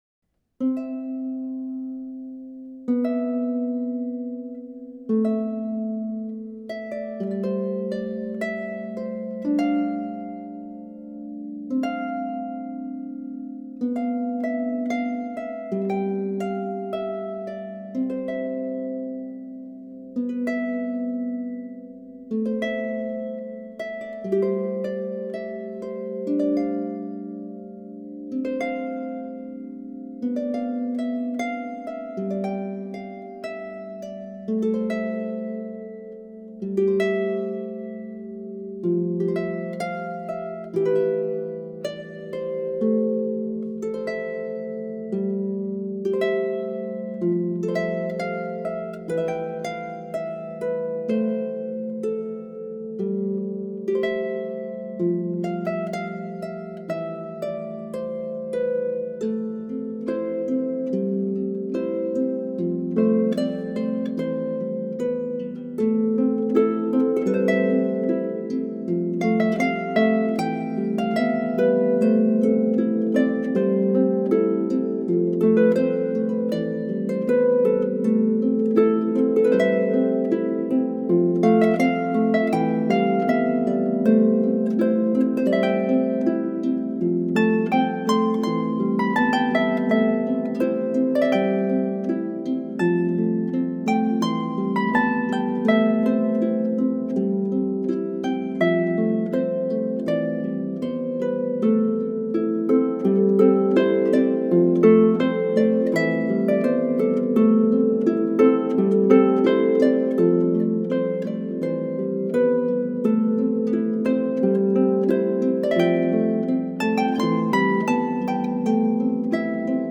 solo lever or pedal harp